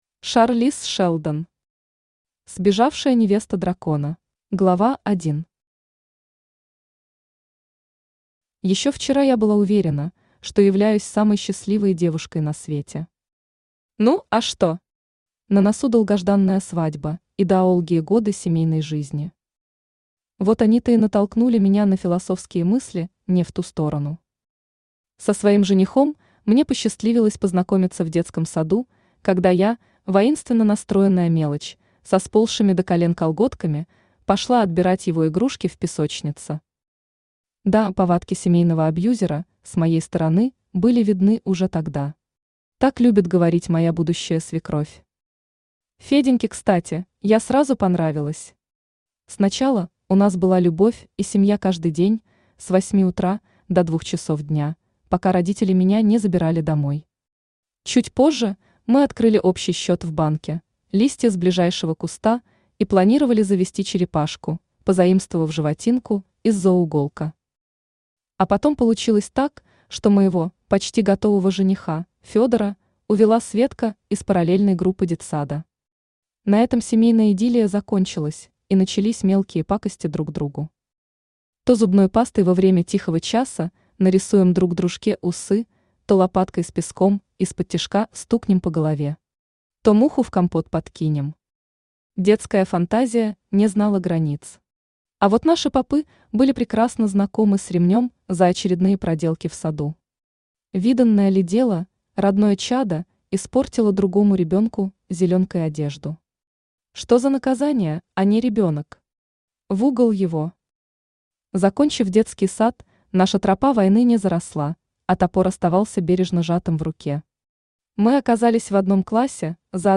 Аудиокнига Сбежавшая невеста дракона | Библиотека аудиокниг
Aудиокнига Сбежавшая невеста дракона Автор Аида Византийская Читает аудиокнигу Авточтец ЛитРес.